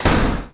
دانلود صدای در 5 از ساعد نیوز با لینک مستقیم و کیفیت بالا
جلوه های صوتی
برچسب: دانلود آهنگ های افکت صوتی اشیاء دانلود آلبوم صدای باز و بسته شدن درب از افکت صوتی اشیاء